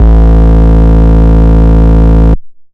Sub Bass (JW3).wav